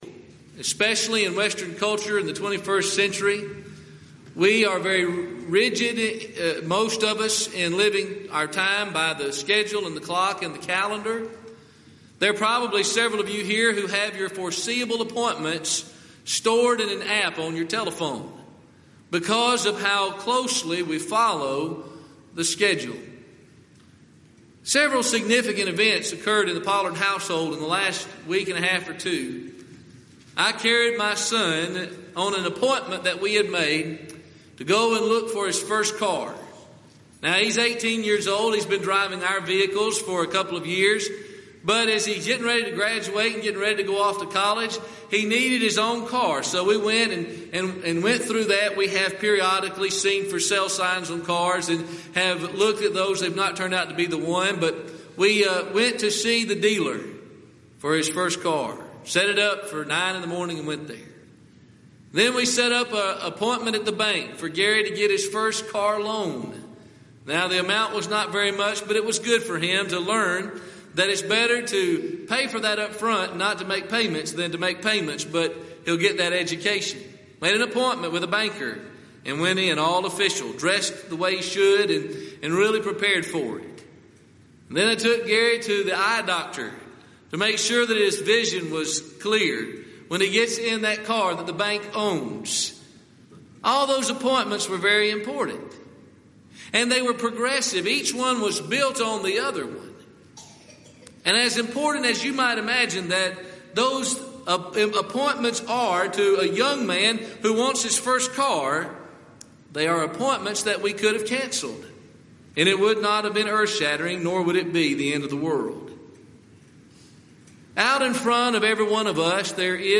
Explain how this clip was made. Event: 31st Annual Southwest Lectures